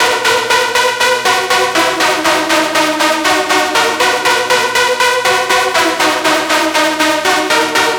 TSNRG2 Lead 013.wav